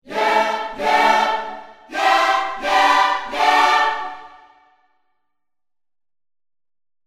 Gospel Yeahs demo =1-A03.mp3